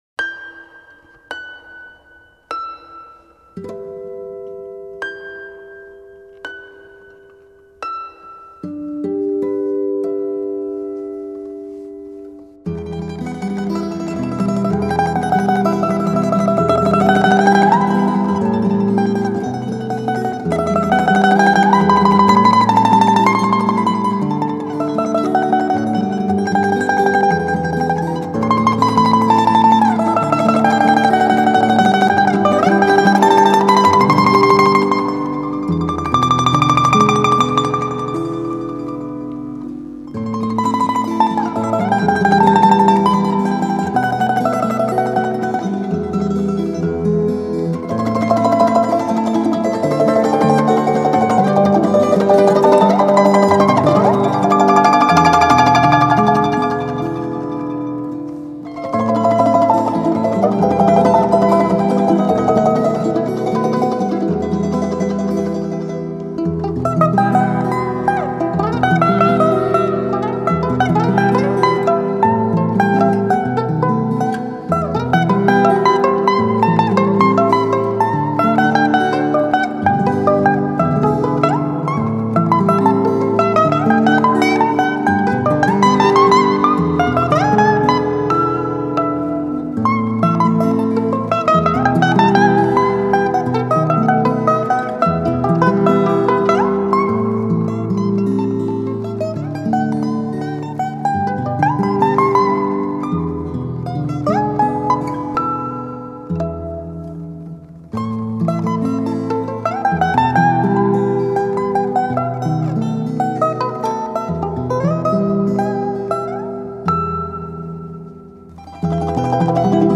Балалайка